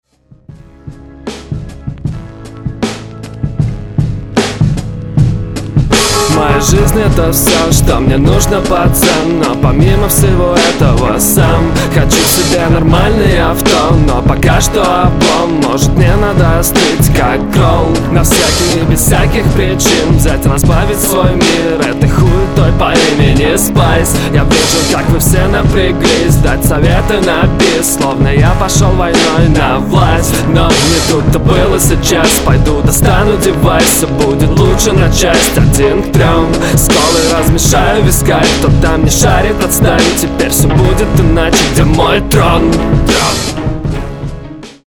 Пытаешься читать напевно, но так в этой напевности фальшивишь, что слушать не слишком приятно.